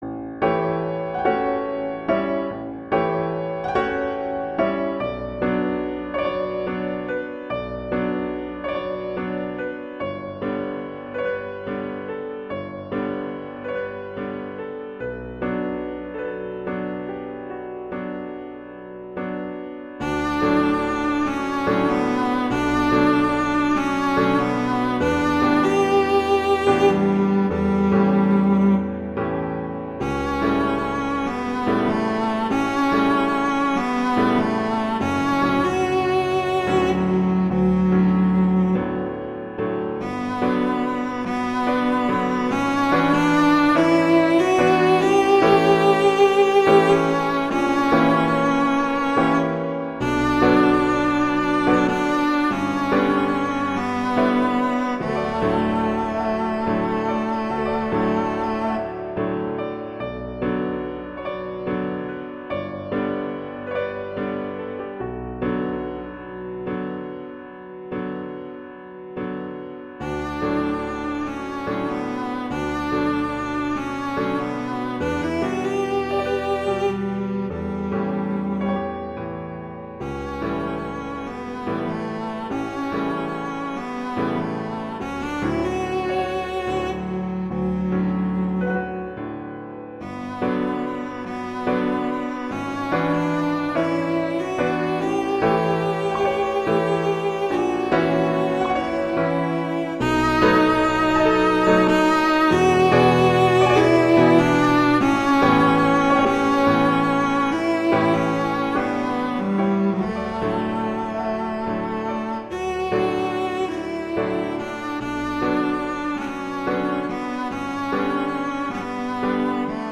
classical
D major
♩. = 48 BPM